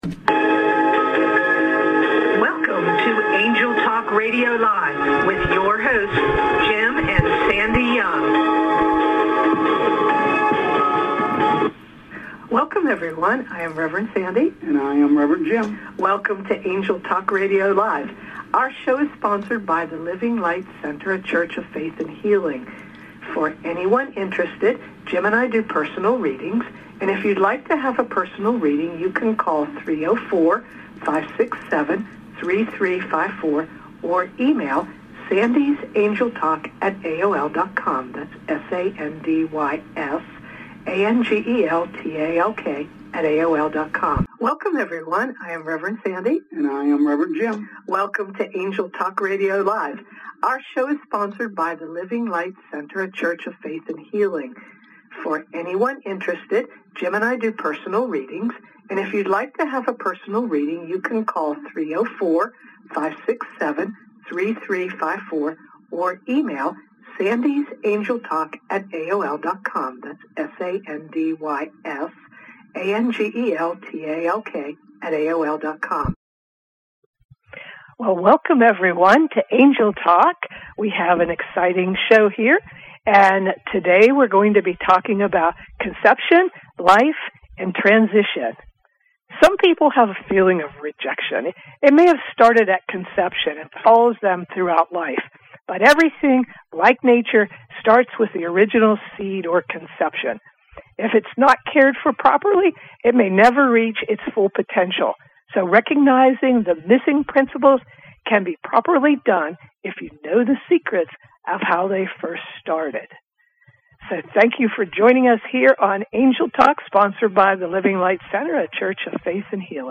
Join us for another exciting Angel Talk radio show.